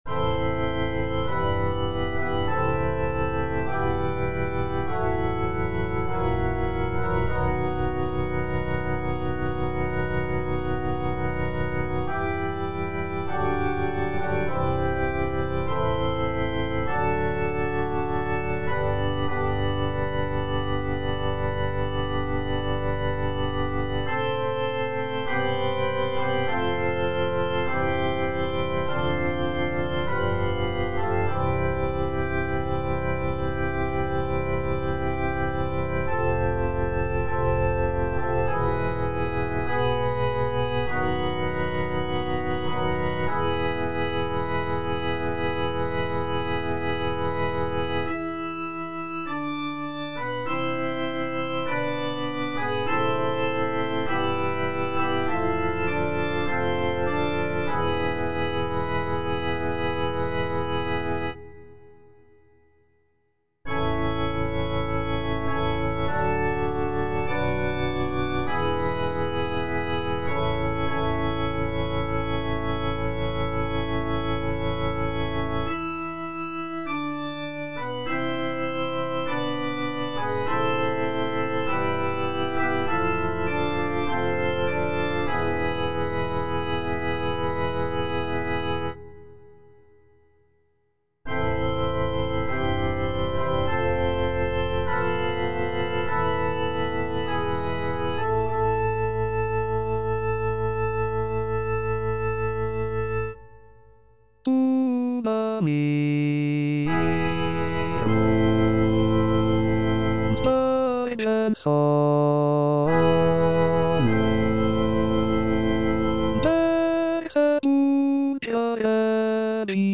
F. VON SUPPÈ - MISSA PRO DEFUNCTIS/REQUIEM Tuba mirum (alto I) (Emphasised voice and other voices) Ads stop: auto-stop
The piece features lush harmonies, soaring melodies, and powerful choral sections that evoke a sense of mourning and reverence.